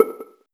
button-hover.wav